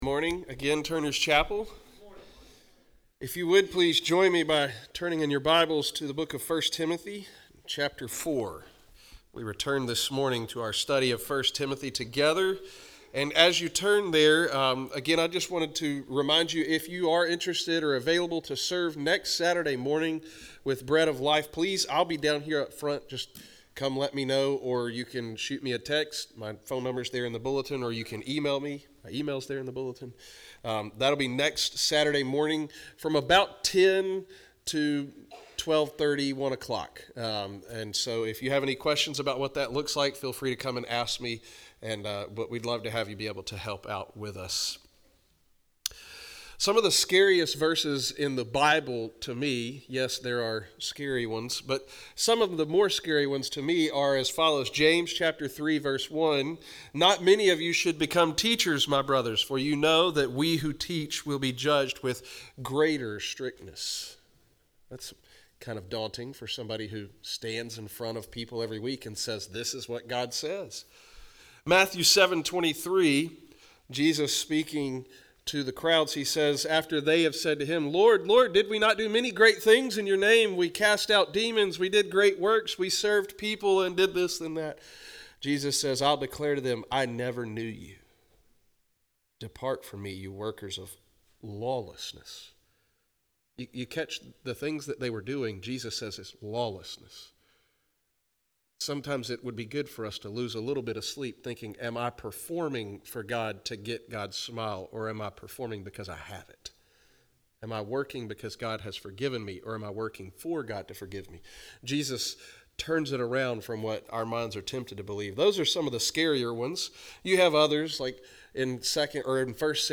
**There is about 30-45 seconds of missing audio around the 9:45 mark. There is only a 5 second gap on this recording to inform the listener that there is a few seconds of missing audio.**